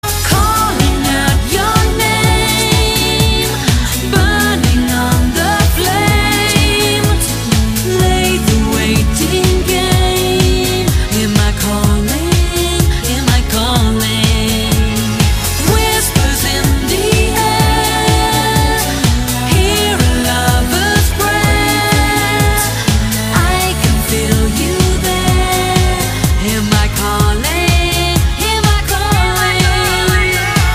• Качество: 160, Stereo
ритмичные
попса
Ритмичная композиция